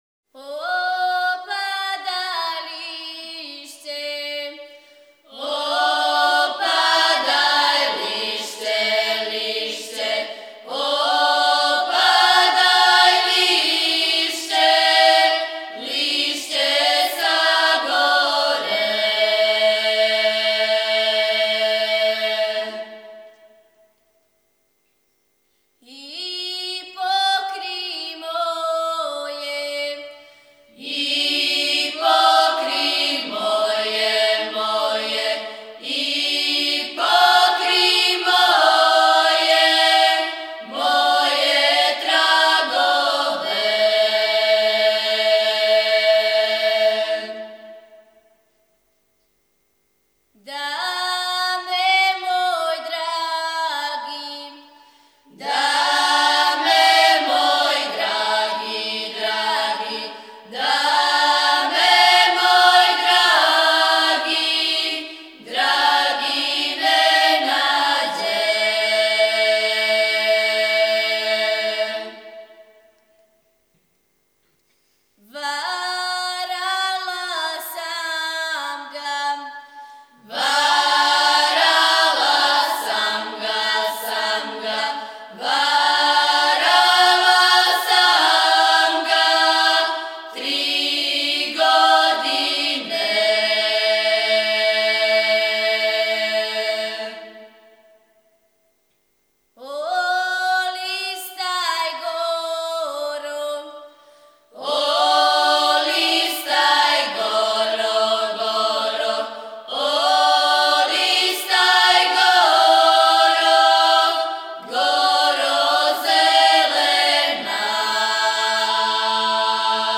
Снимци КУД "Др Младен Стојановић", Младеново - Девојачка и женска певачка група (4.6 MB, mp3) О извођачу Албум Уколико знате стихове ове песме, молимо Вас да нам их пошаљете .